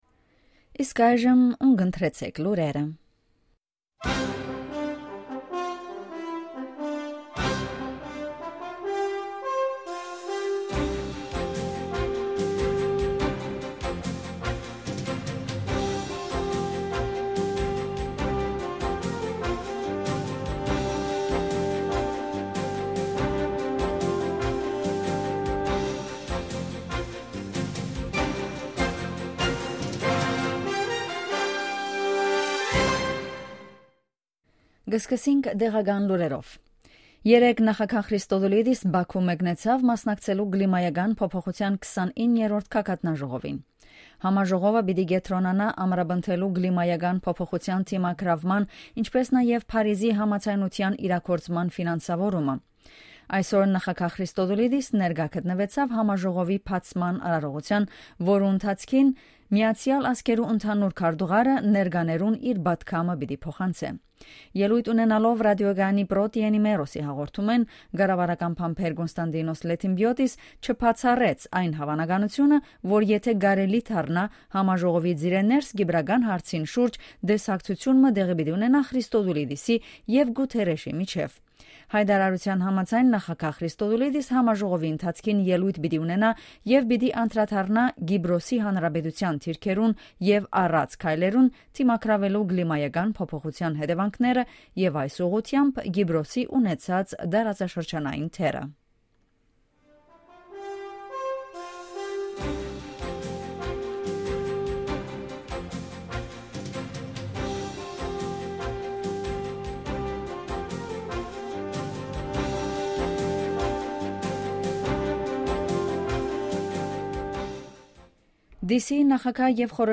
Ειδήσεις στα Αρμένικα - News in Armenian
Καθημερινές Ειδήσεις στα Αρμένικα. Daily News in Armenian.